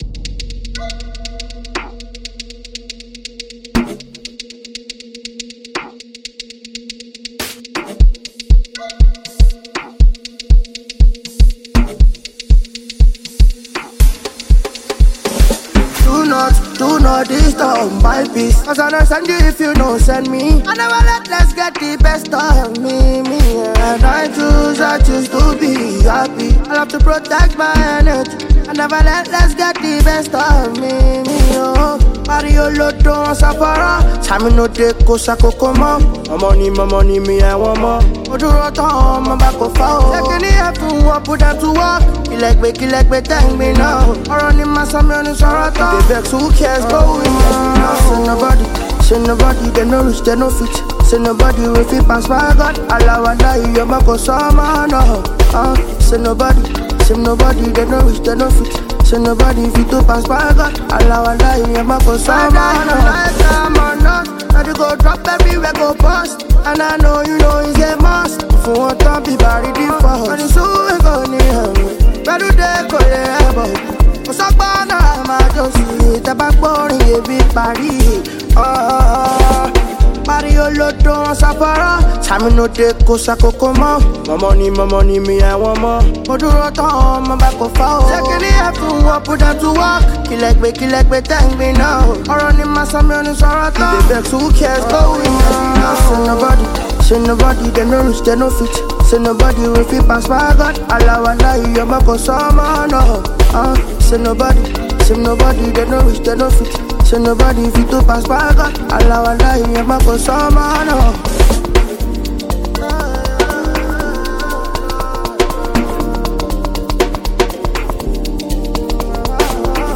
Afrobeats
Afrobeat with contemporary influences